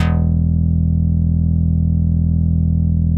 MICROFUNK E2.wav